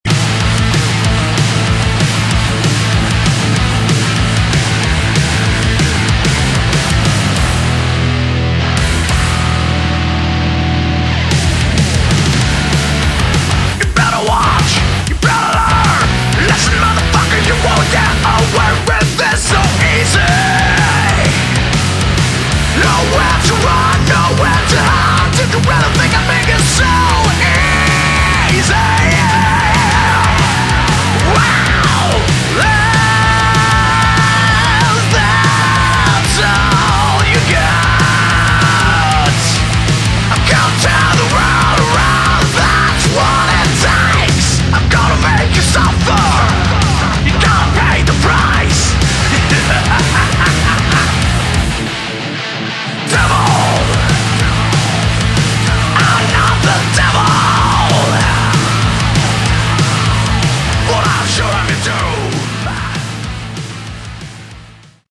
Category: Hard Rock
vocals, rhythm guitars
bass, keyboards
lead guitars
drums